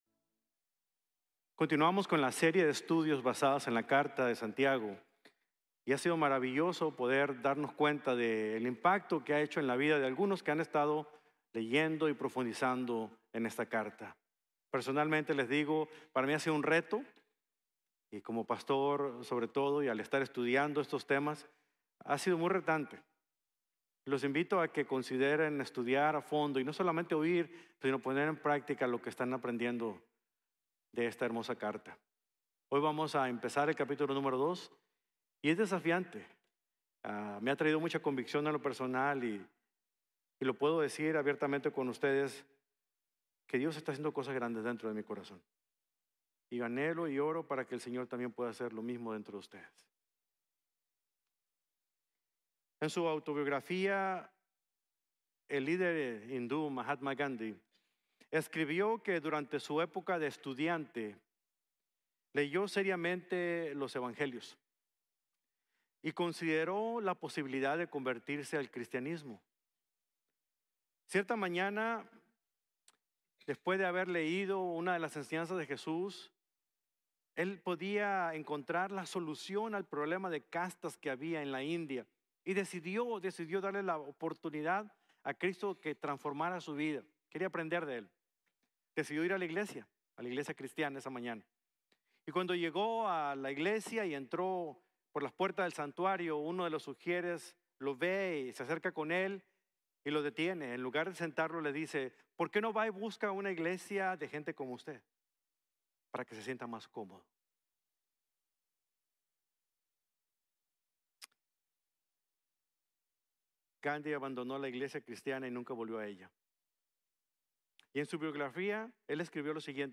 No Muestres Parcialidad | Sermon | Grace Bible Church